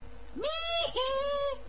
Llamas say meeeheee, when they have a retarded moment...